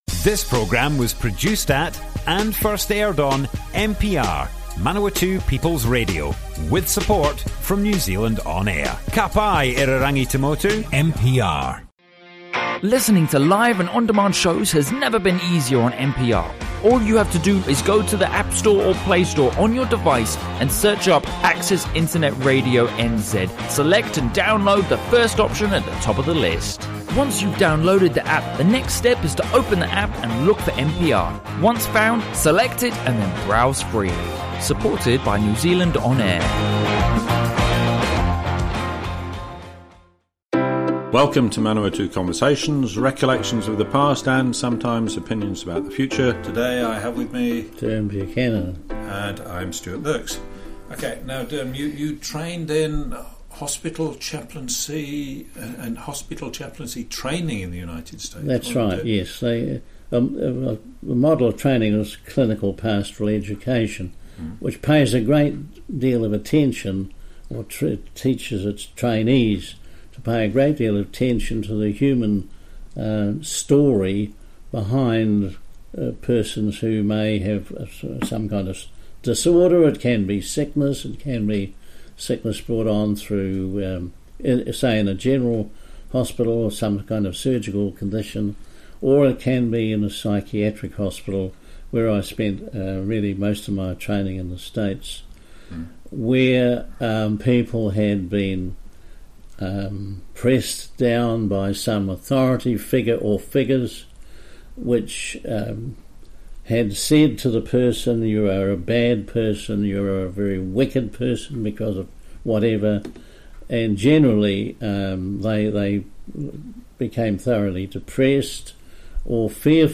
Broadcast on Manawatū People's Radio, 3rd September 2019.